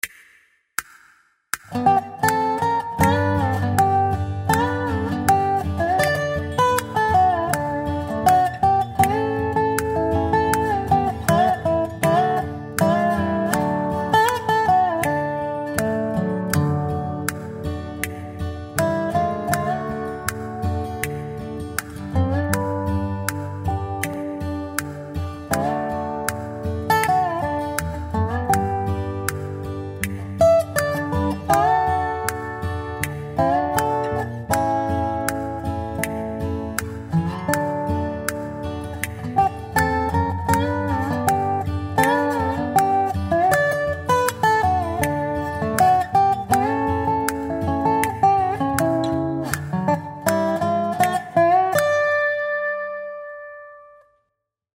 v D Dur